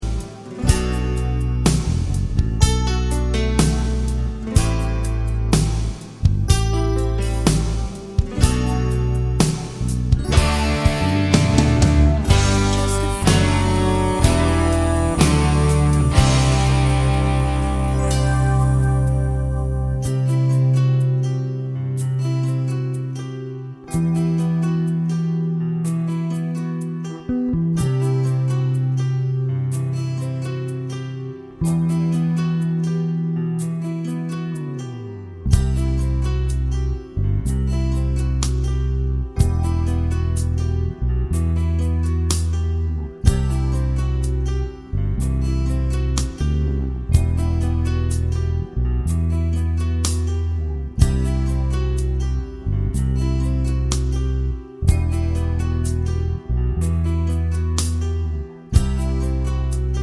Minus Sax Pop (1980s) 4:05 Buy £1.50